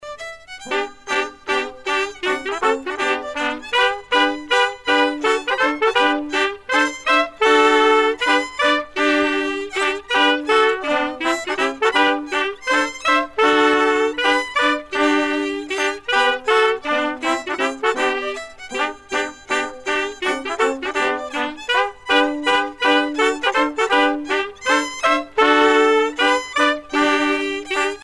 danse : scottich sept pas
groupe folklorique
Pièce musicale éditée